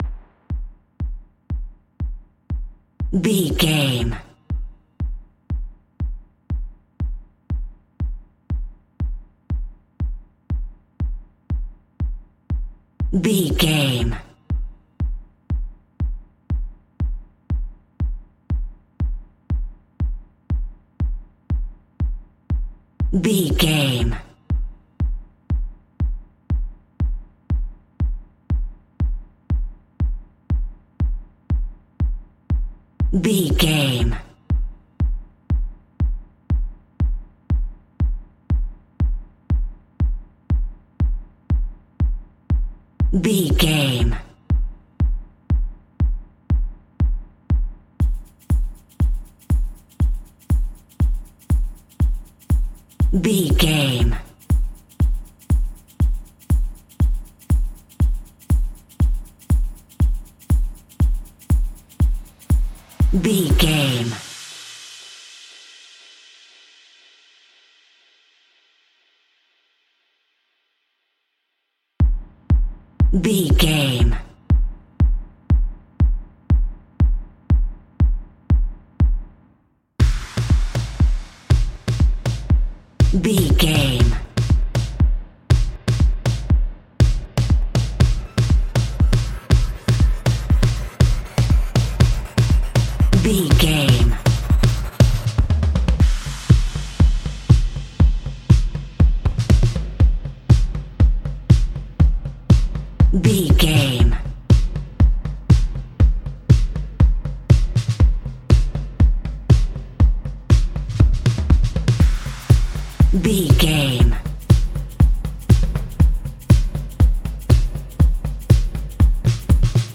Ionian/Major
uplifting
percussion